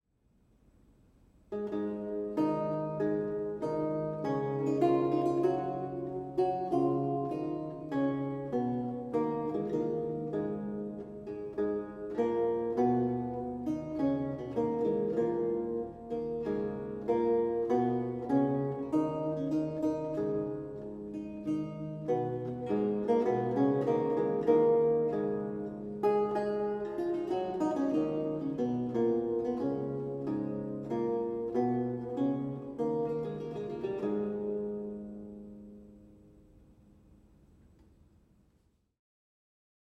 a 16th century lute music piece originally notated in lute tablature
Audio recording of a lute piece from the E-LAUTE project